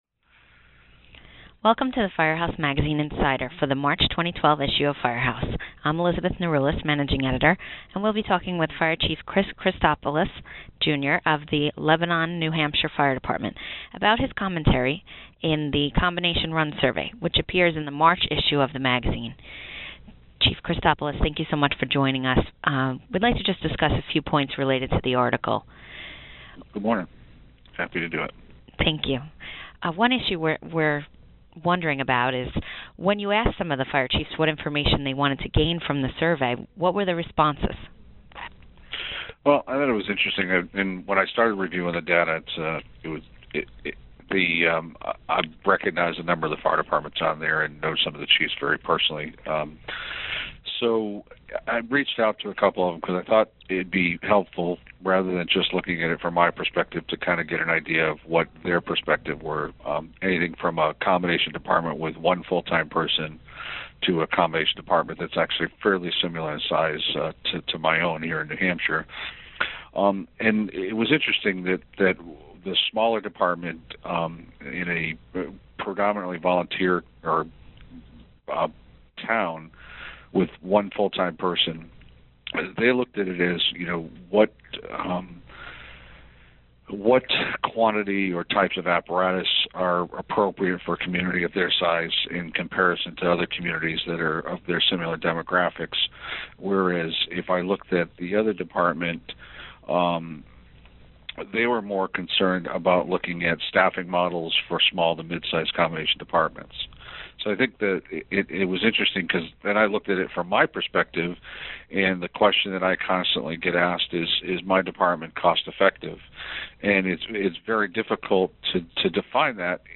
Listen as the two discuss the value to collecting this data for combination fire departments and what types of information chiefs hoped to gain from it.